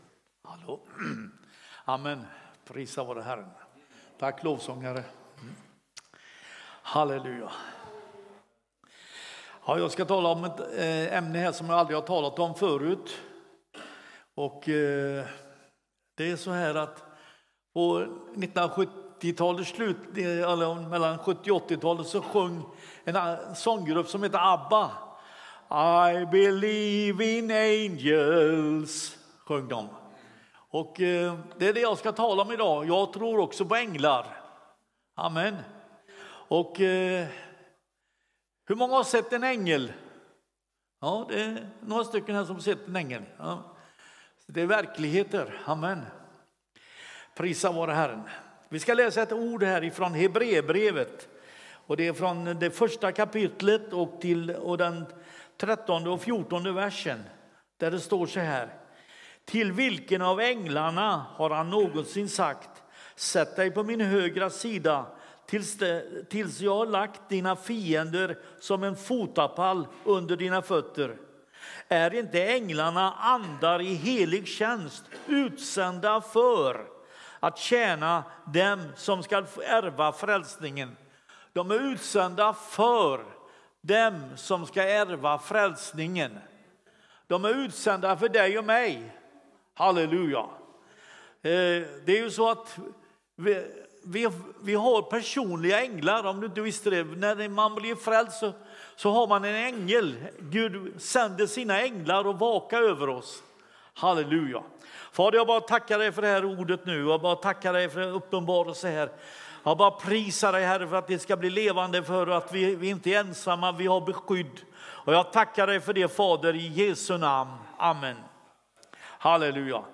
Pingst Västerås söndag 19 oktober 2025